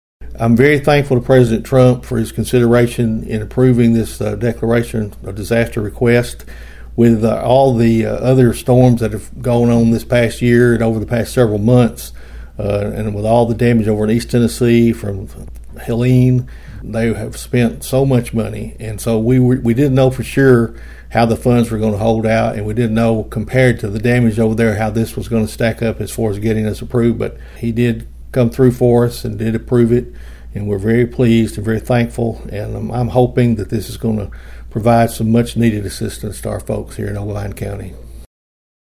The Mayor said he was grateful that Obion County was awarded the federal Disaster Declaration to assist those affected by the storms and flooding.(AUDIO)